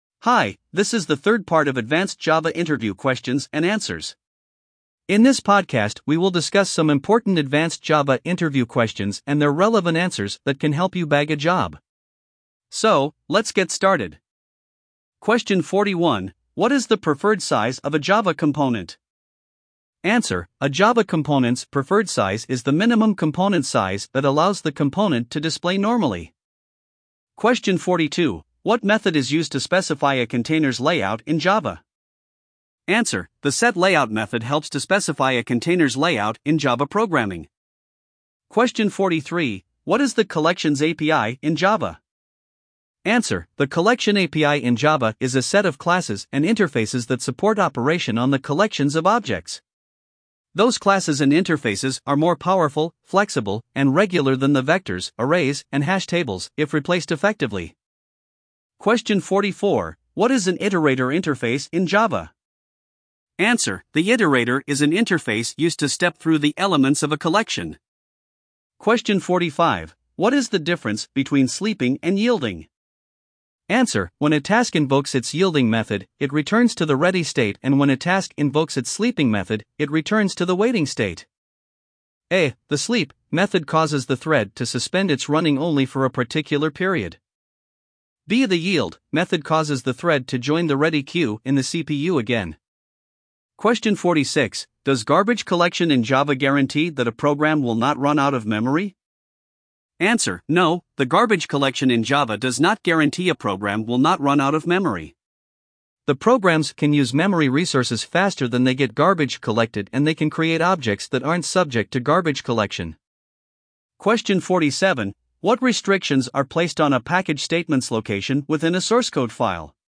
LISTEN TO THE ADVANCED JAVA FAQs LIKE AN AUDIOBOOK